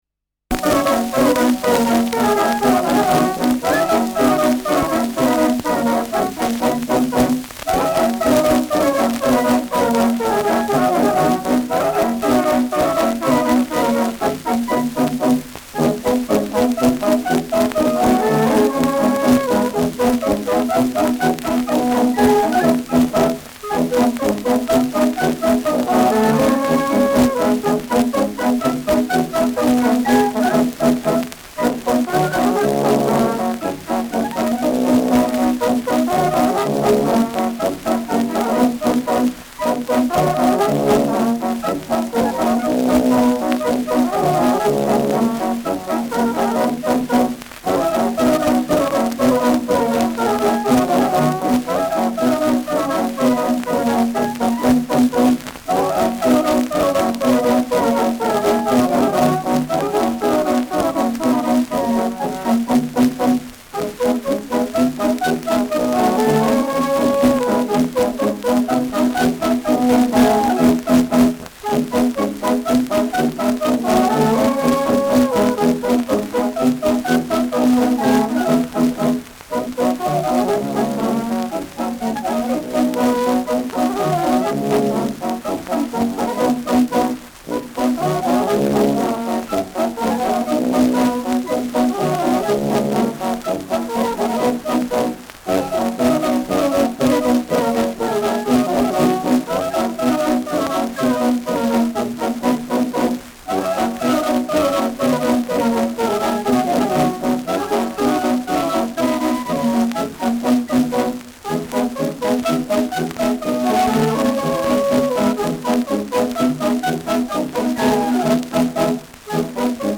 Schellackplatte
Teil der Platte ausgebrochen, von dort digitalisiert : Abgespielt : Erhöhtes Grundrauschen : Vereinzelt leichtes Knacken